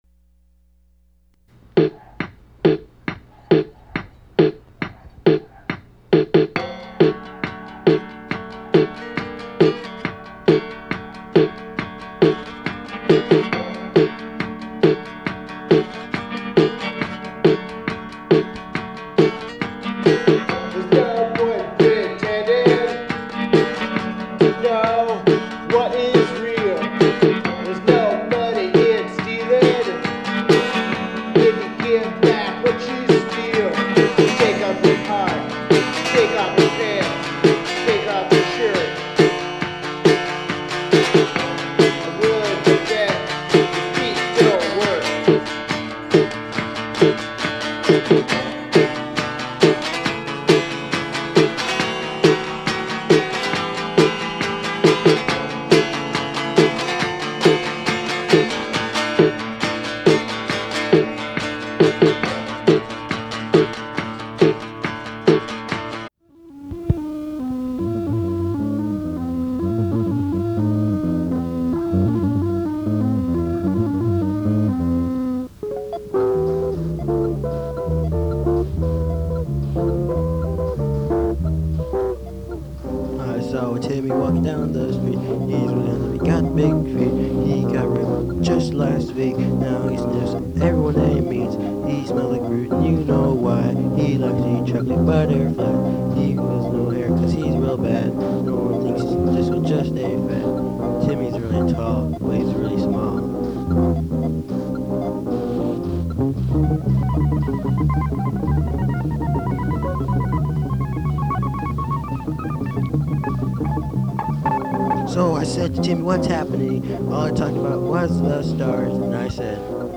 The CD varies wildly in style and recording
howl and wail.